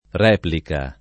replica [ r $ plika ] s. f.